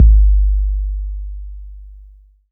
808-Kicks02.wav